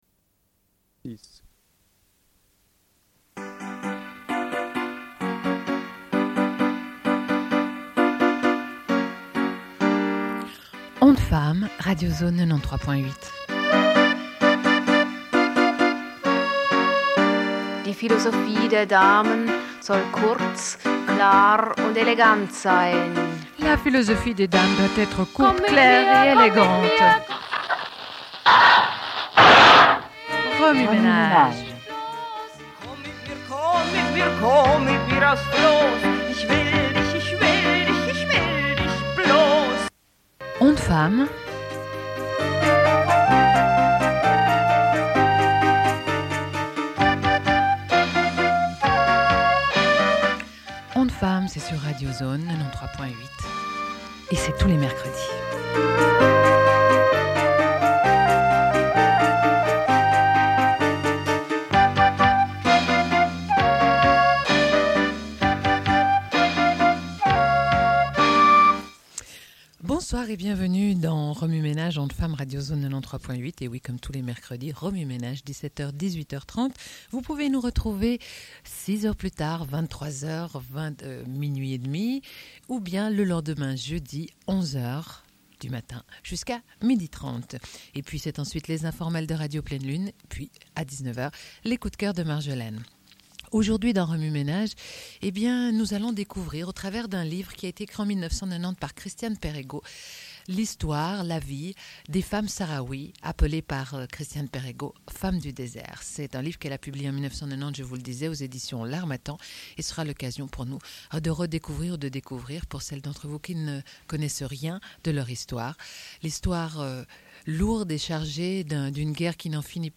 Une cassette audio, face A
Genre access points Radio